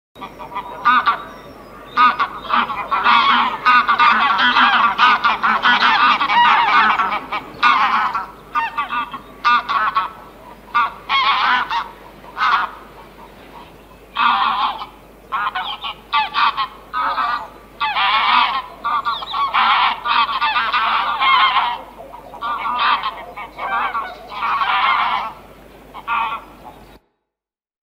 На этой странице собраны звуки гусей – от привычного бормотания до громких криков.
Подборка включает голоса как домашних, так и диких гусей, обитающих у водоемов.
Крик дикого гуся